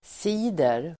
Ladda ner uttalet
Uttal: [s'i:der]